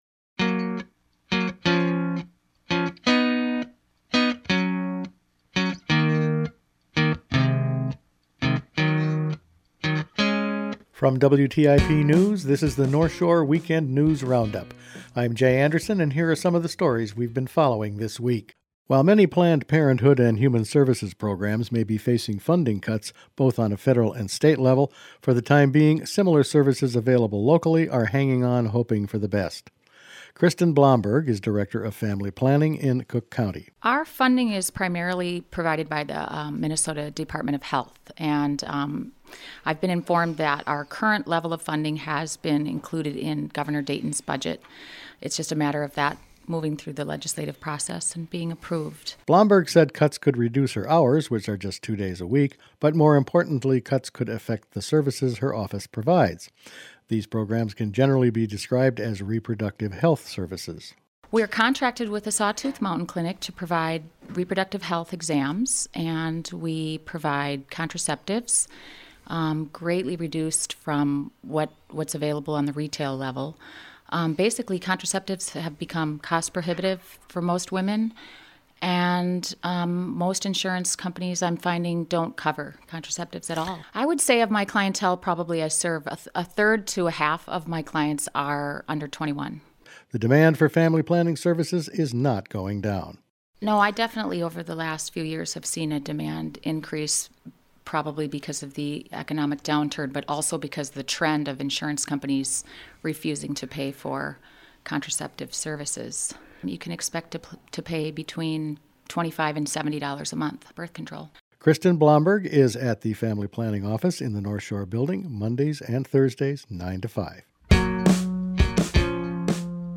Weekend News Roundup for March 5